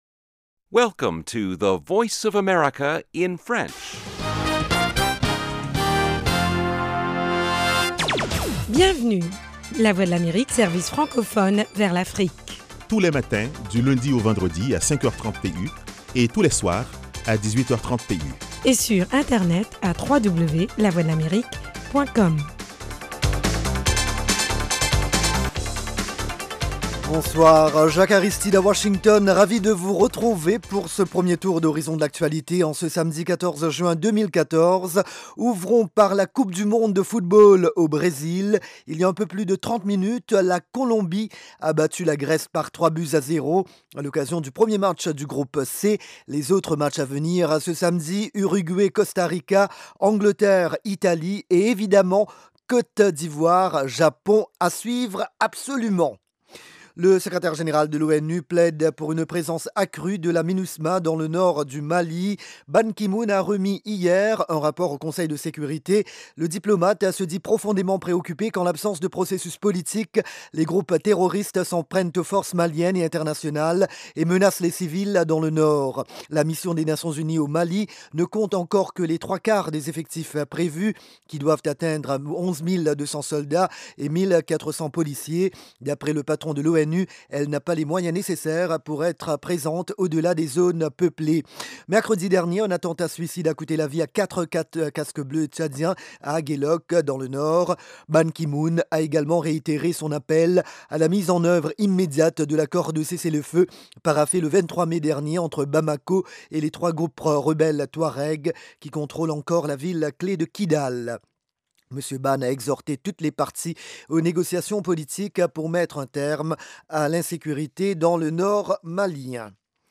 Le magazine vous tient aussi au courant des dernières découvertes en matière de technologie et de recherche médicale. Carrefour Santé et Sciences vous propose aussi des reportages sur le terrain concernant les maladies endémiques du continent : paludisme, sida, polio, grippe aviaire…